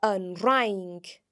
In most instances, the Gaelic fh sound is silent, with a few exceptions that we shall explore in a little bit.